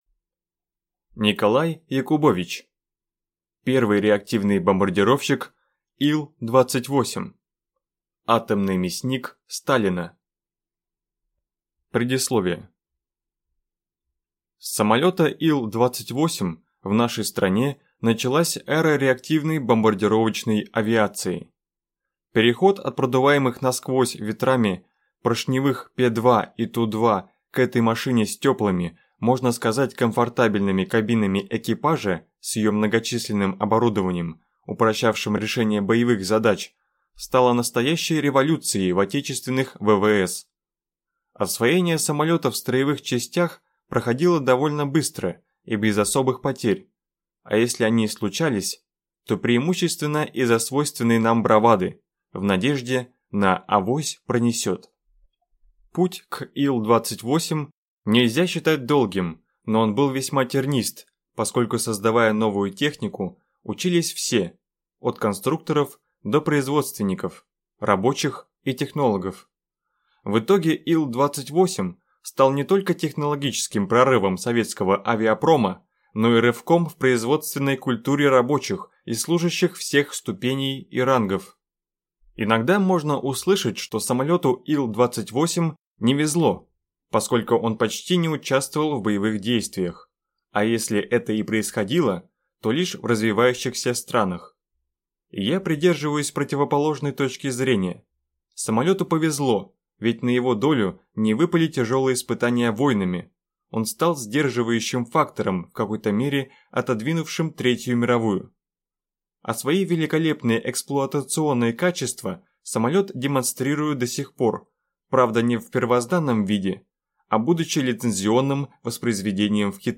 Аудиокнига Первый реактивный бомбардировщик Ил-28. Атомный «мясник» Сталина | Библиотека аудиокниг